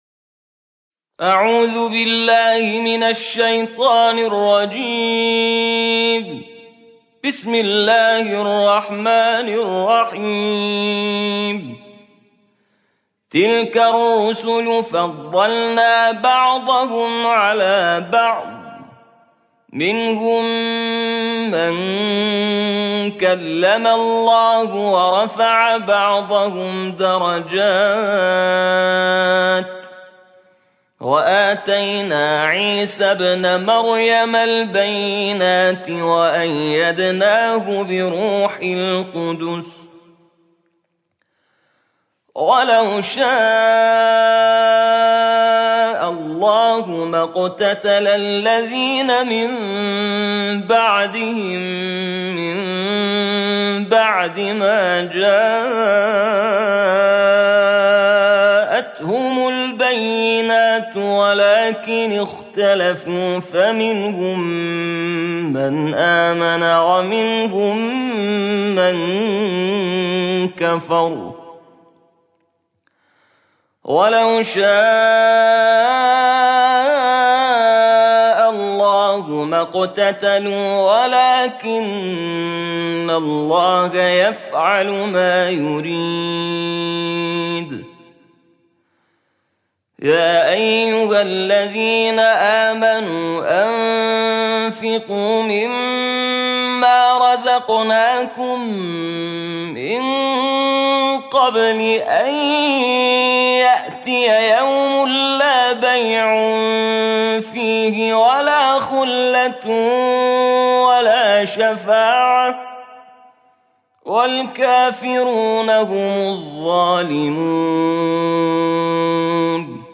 صوت | ترتیل جزء سوم قرآن